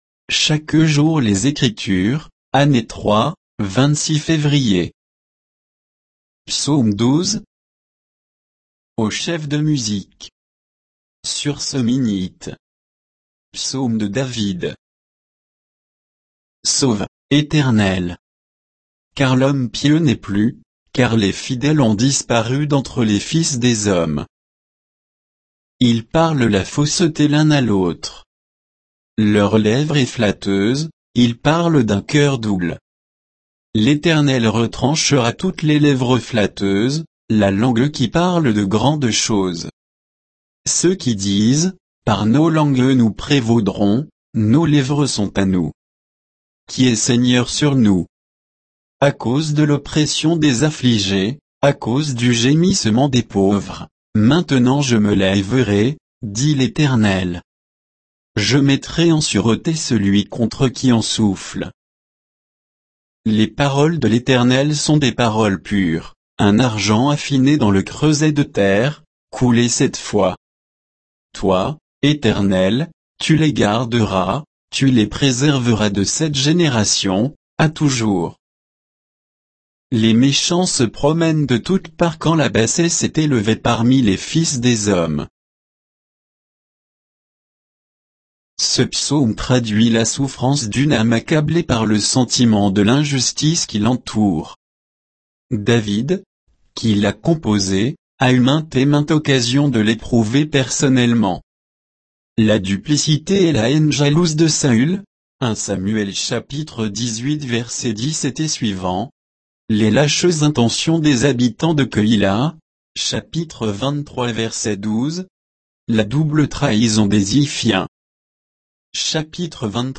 Méditation quoditienne de Chaque jour les Écritures sur Psaume 12